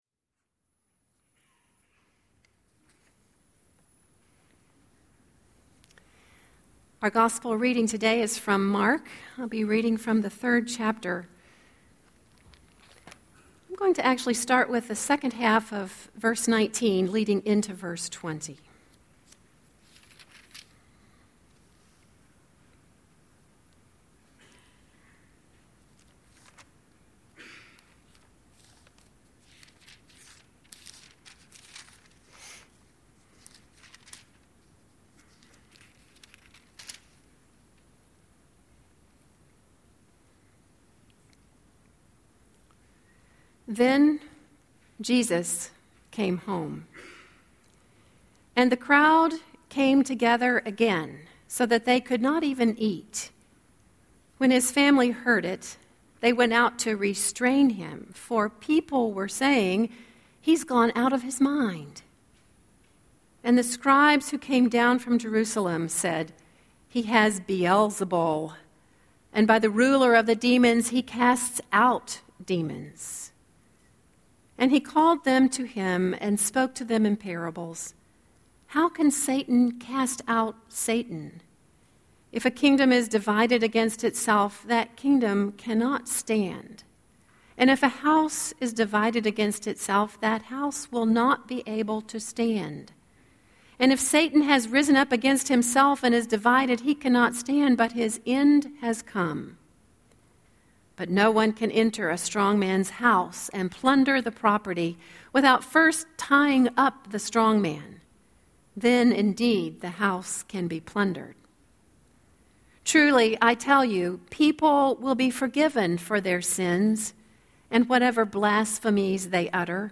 06-07-Scripture-and-Sermon.mp3